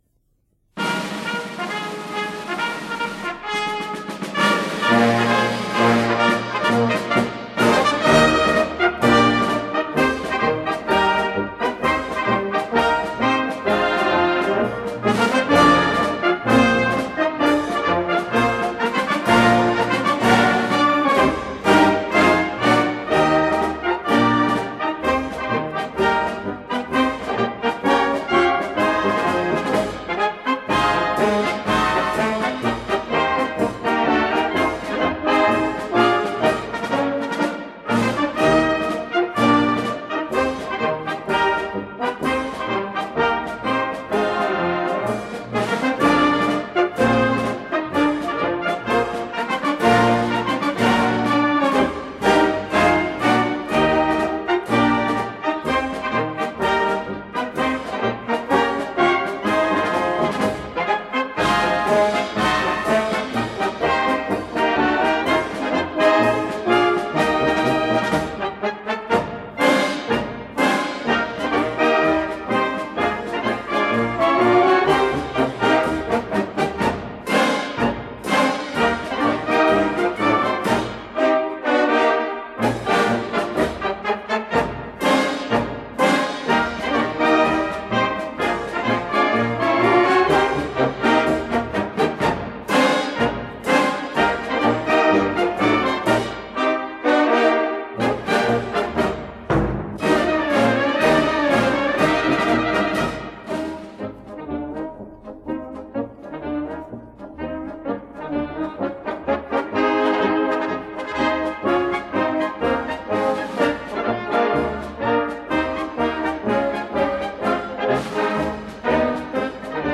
Besetzung: Concert Band